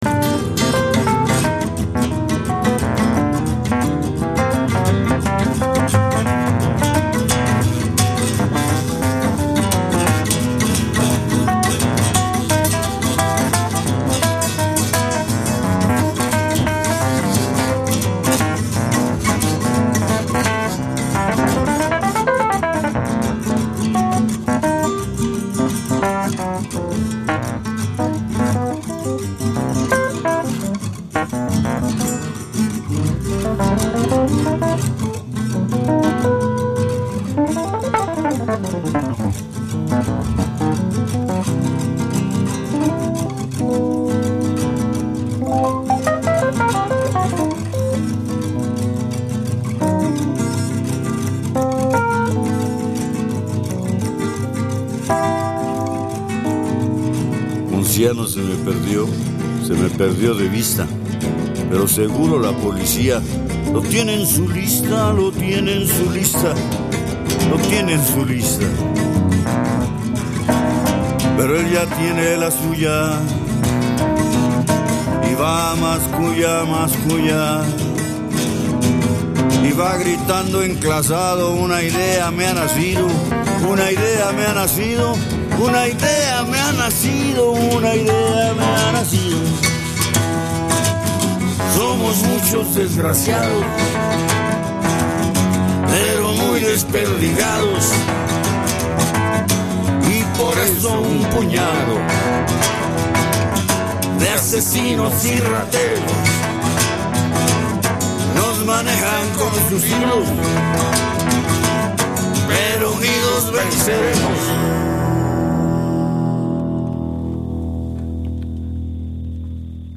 New LatinAmerican tendencies, playlist